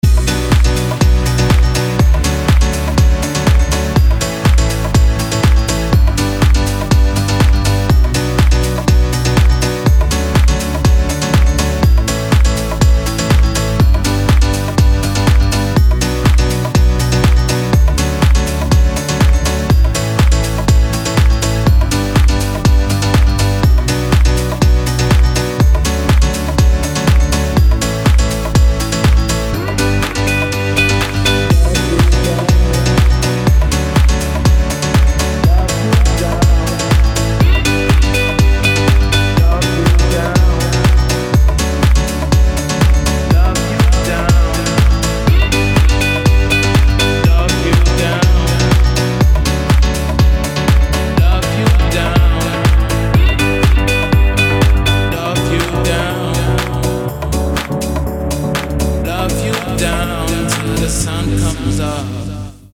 • Качество: 256, Stereo
deep house
dance
Electronic
электронная музыка
пианино
house